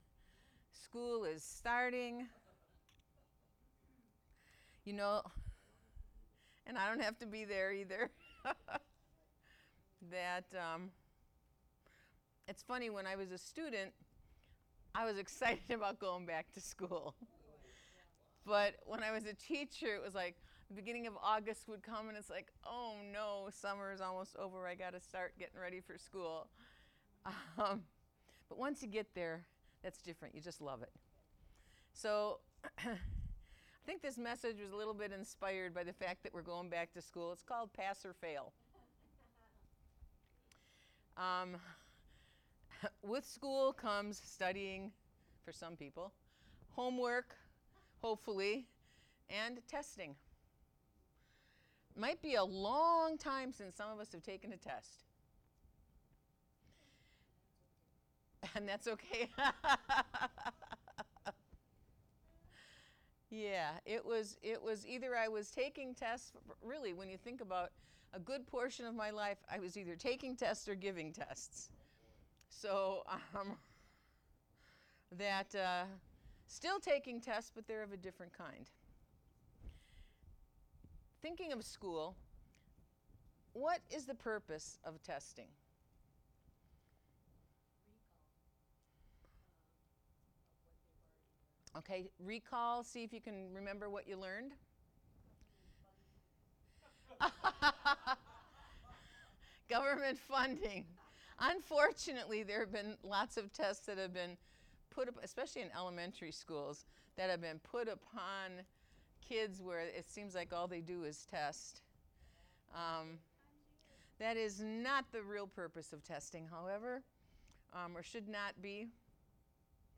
Sermons | The City of Hope